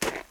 PixelPerfectionCE/assets/minecraft/sounds/step/snow1.ogg at mc116
snow1.ogg